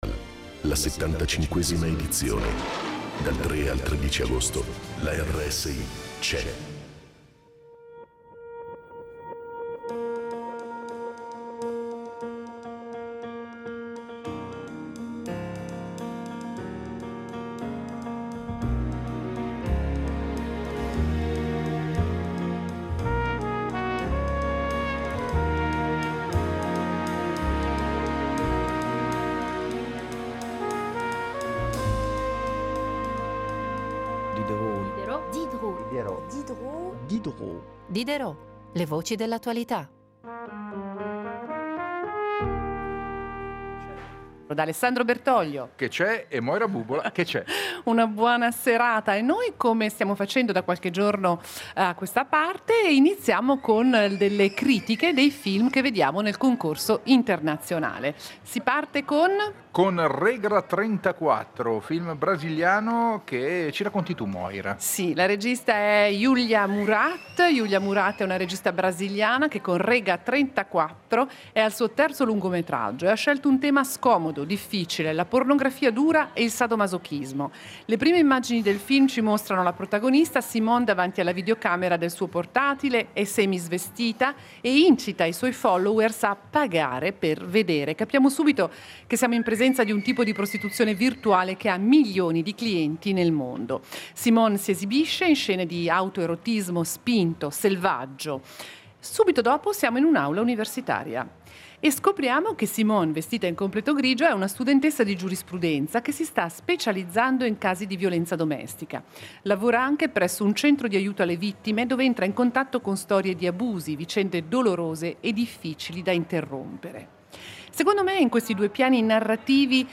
Diderot dedica la prima mezz’ora del programma al Locarno Film Festival , nei giorni in cui si svolge la sua 75ma edizione.